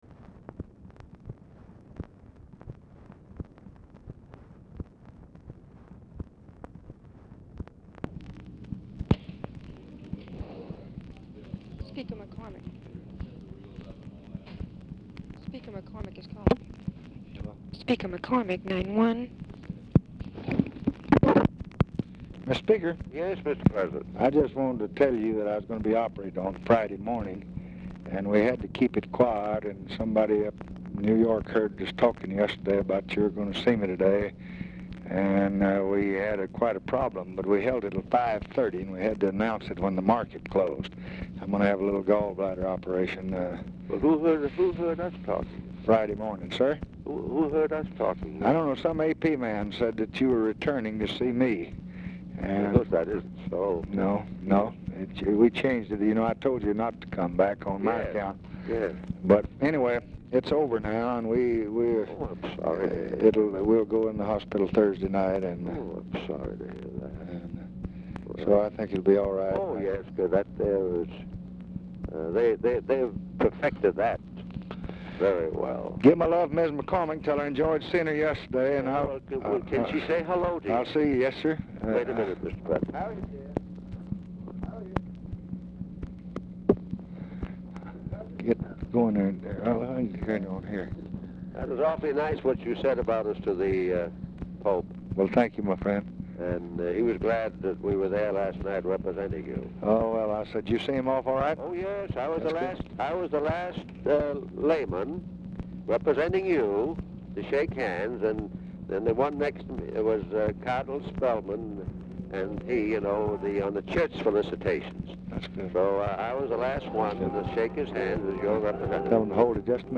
Title Telephone conversation # 9016
TV AUDIBLE IN BACKGROUND
Format Dictation belt